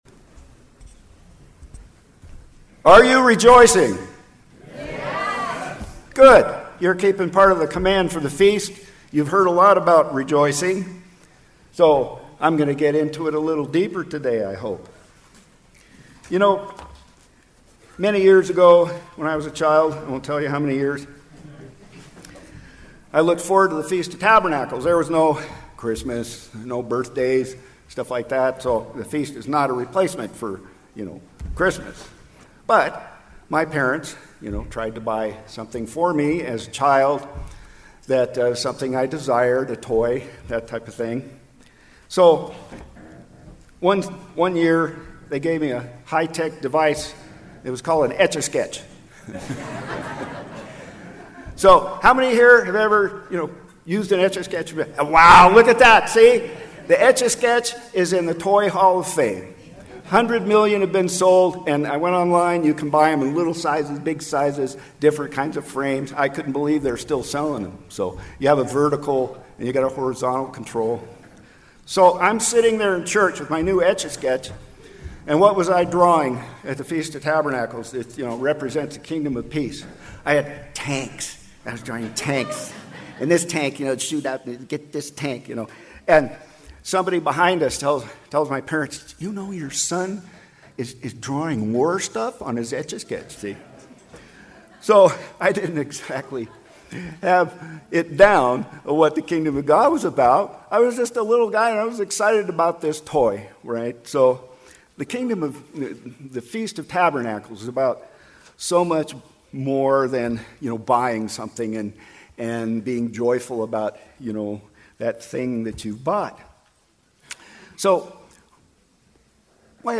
This sermon was given at the Klamath Falls, Oregon 2024 Feast site.